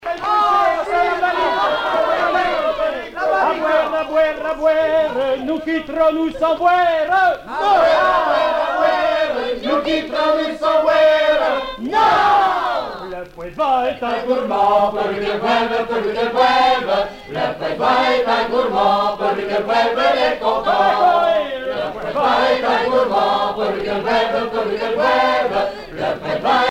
Airs traditionnels de noces poitevine - A boire à boire
Pièce musicale éditée